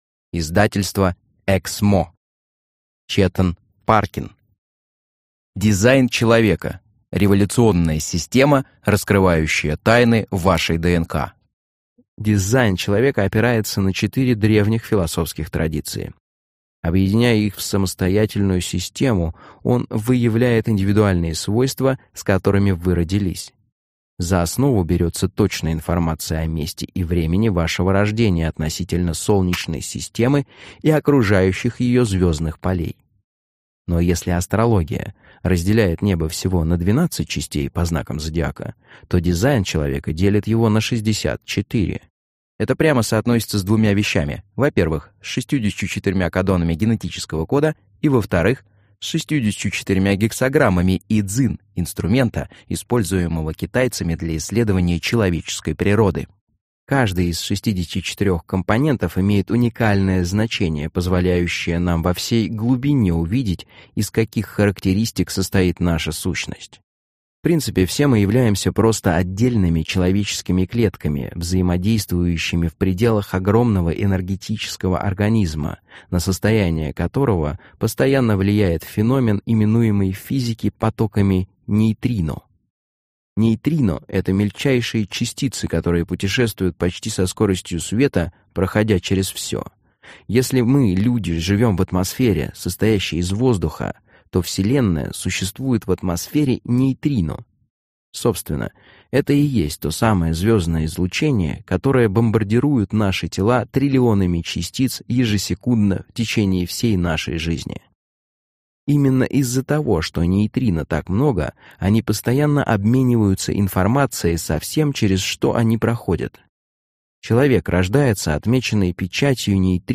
Aудиокнига Дизайн Человека. Откройте Человека, Которым Вы Были Рождены